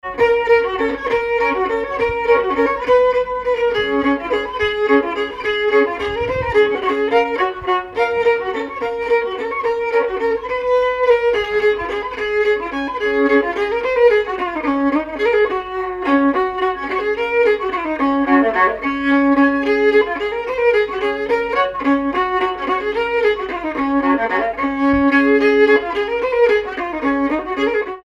Valse rapide
danse : valse
circonstance : bal, dancerie
Pièce musicale inédite